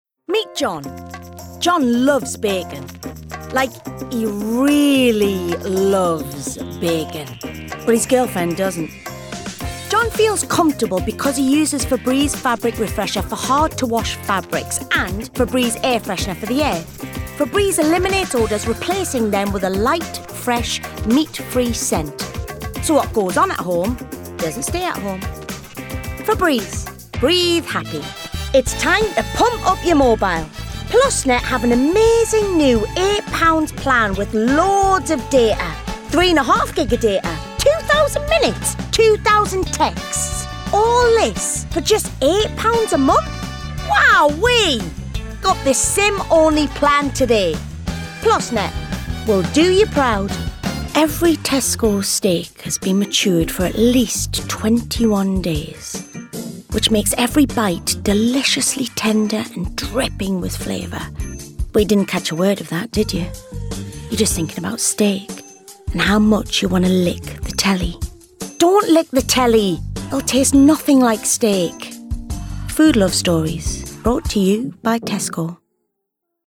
Geordie
Range 40s and over
An experienced actress and performer with a friendly & bubbly delivery.